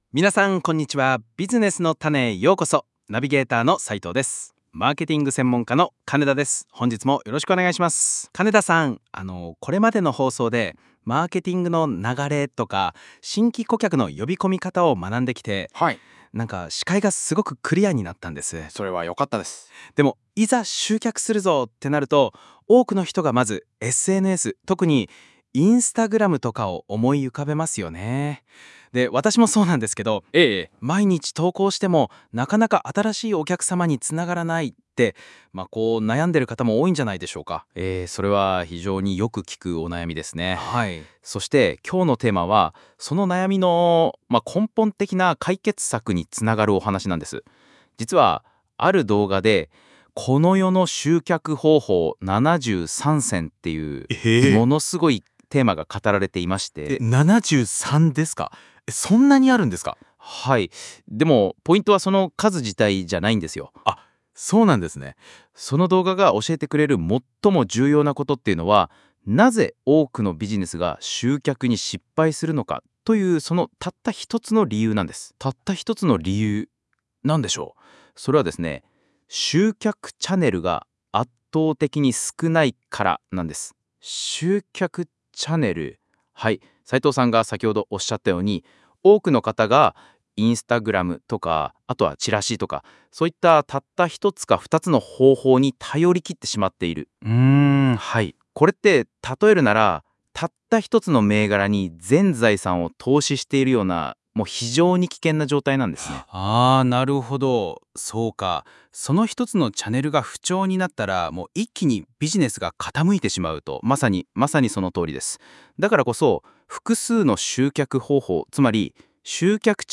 会話の文字起こしはこちら↓